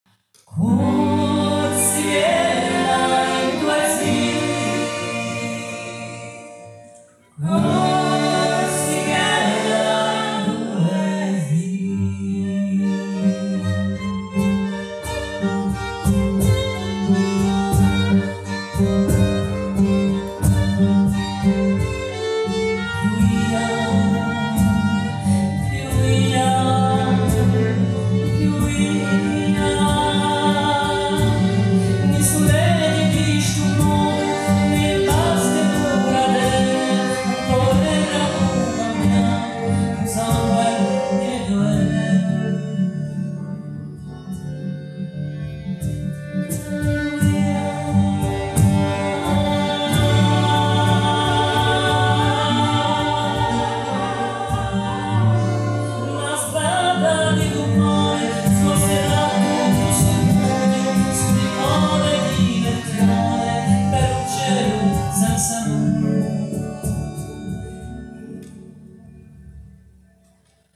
Groupe de musique de Méditerranée et d'ailleurs